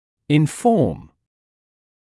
[ɪn’fɔːm][ин’фоːм]информировать, сообщать